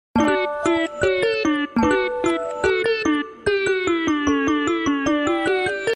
Kitten Meow Ringtone Samsung 🐱😁 sound effects free download